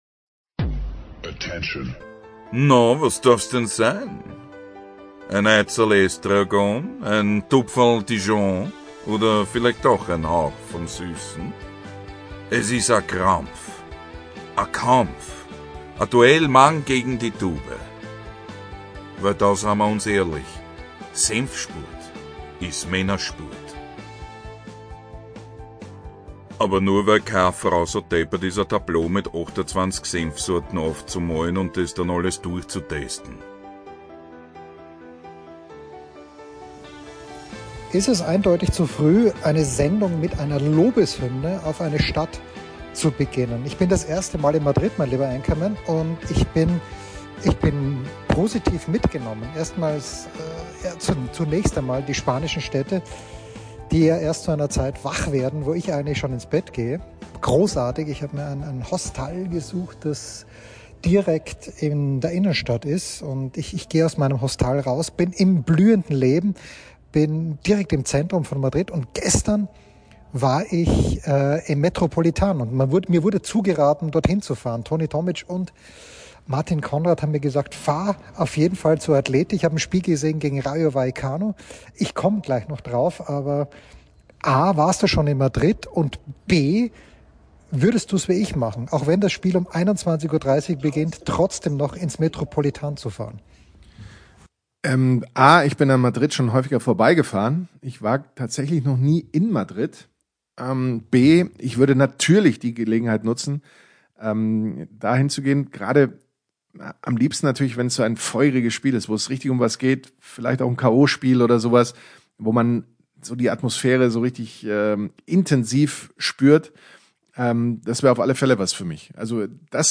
Die kulinarische Versorgung in Madrid ist gar prächtig, das Internet ist es nicht.